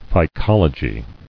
[phy·col·o·gy]